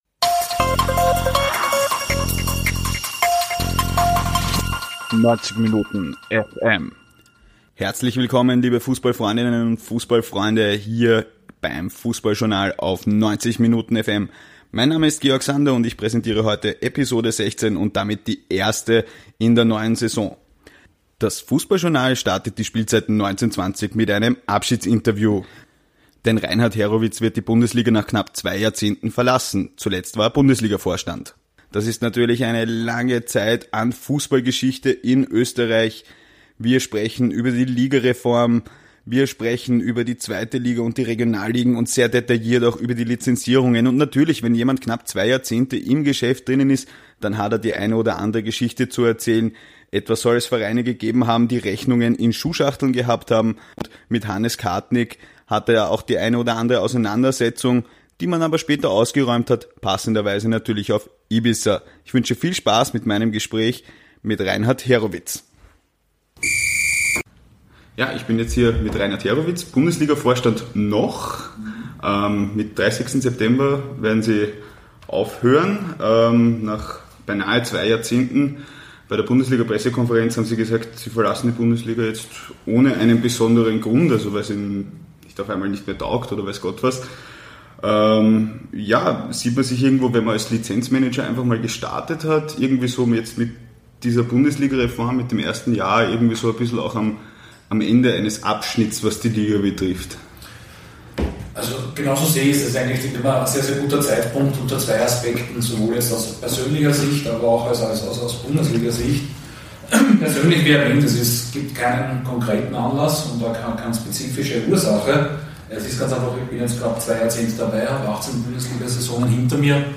Im Interview lässt er die Zeit bei der Liga Revue passieren, erzählt Anektoden von den frühen Jahren mit Zampanos wie Hannes Kartnig und von Klubs, die die Rechnungen in der Schuhschachtel aufbewahrten und gibt interessante Einblicke in den Lizenzierungsprozess.